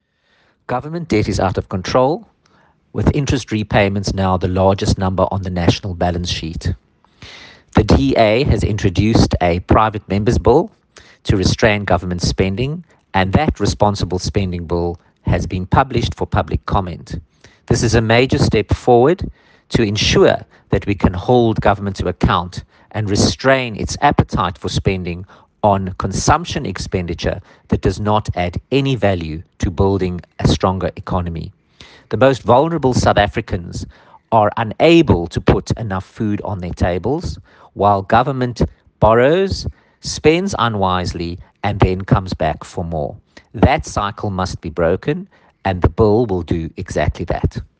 soundbite by Dr Dion George MP.